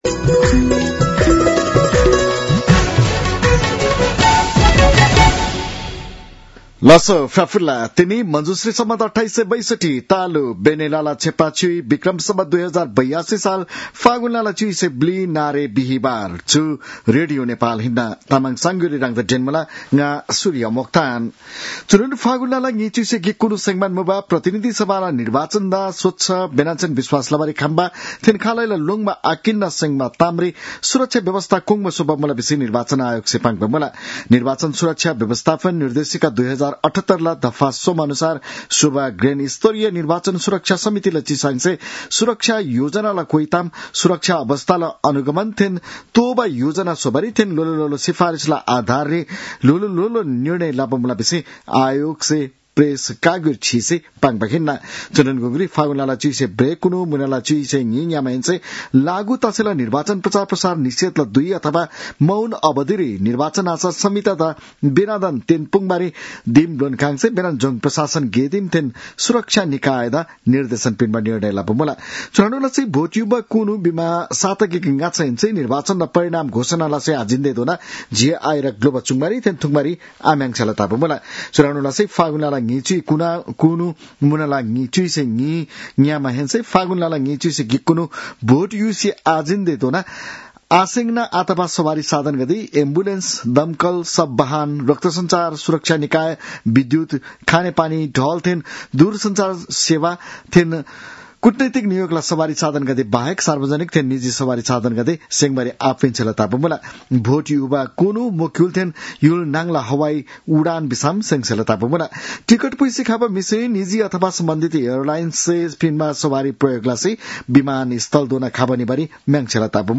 तामाङ भाषाको समाचार : १४ फागुन , २०८२